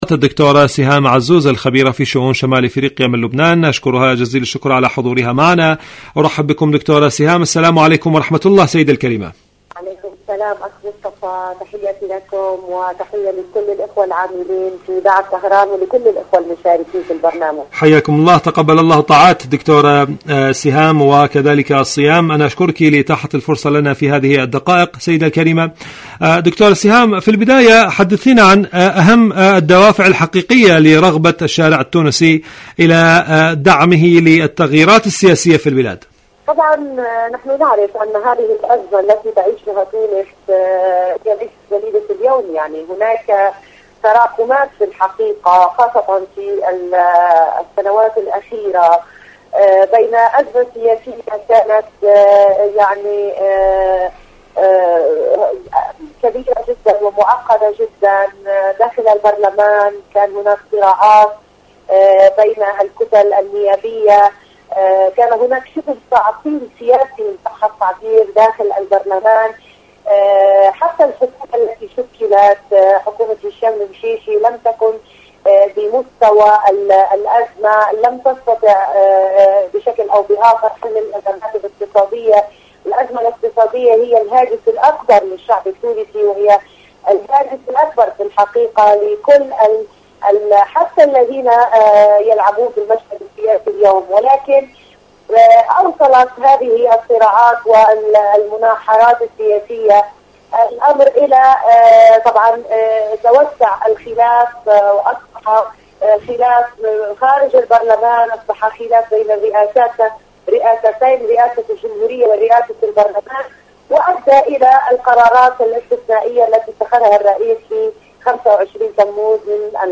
مقابلات برامج إذاعة طهران العربية برنامج صدى المغرب العربي مقابلات إذاعية المرحلة الانتخابية الانتخابات التونسية تونس الحكومة التونسية شاركوا هذا الخبر مع أصدقائكم ذات صلة الردع الإيراني والمقاومة الفلسطينية..